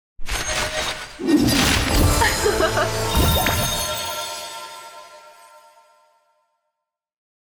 sfx-nacho-intro-skin-sett-anim.exalted_sett_sanctum_banner.ogg